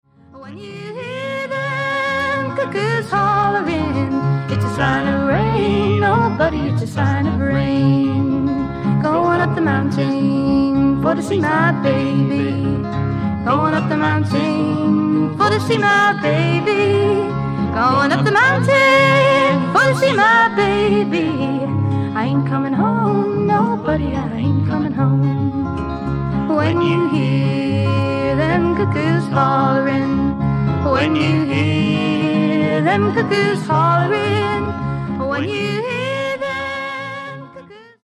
FEMALE FOLK / PSYCHEDEIC POP